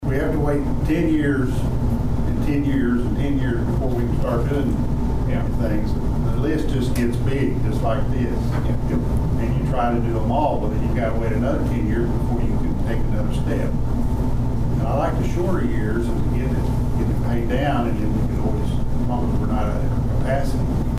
Dewey City Council met for the first time in the month of June on Monday night at Dewey City Hall.
Mayor Tom Hays talked about the advantages of a shorter time period between bond projects.